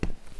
descargar sonido mp3 paso
sonidos-ambientales-y-de-investigacion_3.mp3